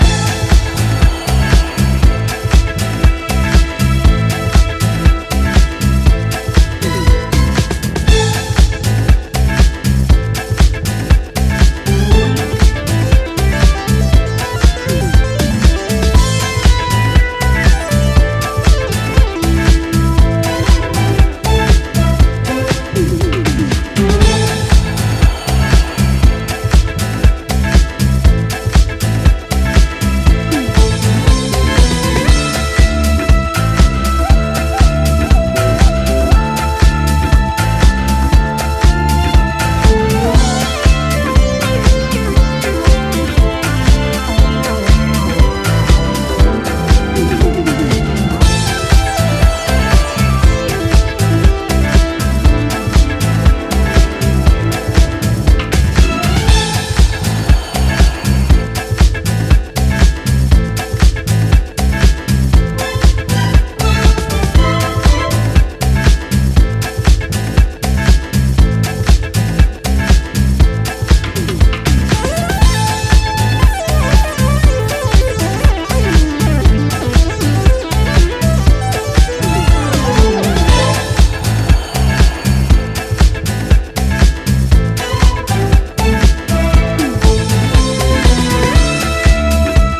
ジャンル(スタイル) DISCO HOUSE / DEEP HOUSE / POP